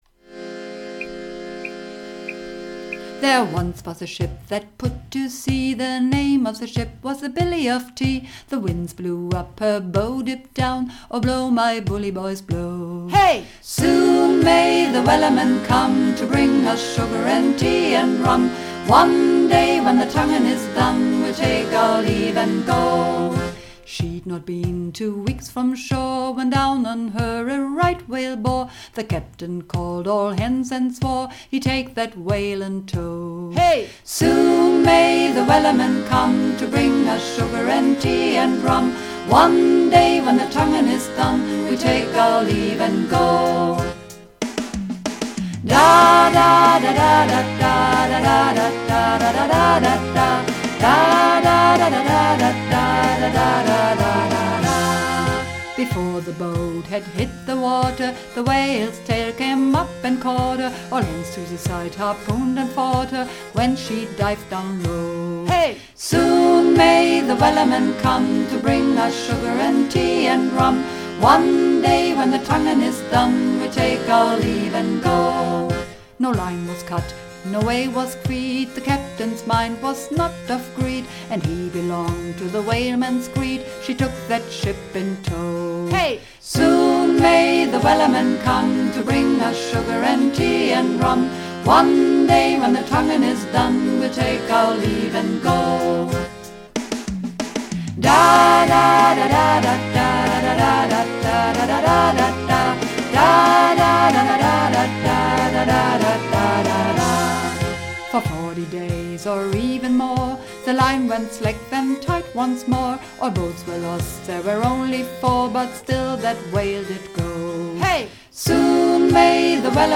Übungsaufnahmen - Wellerman
Runterladen (Mit rechter Maustaste anklicken, Menübefehl auswählen)   Wellerman (Mehrstimmig)
Wellerman__4_Mehrstimmig.mp3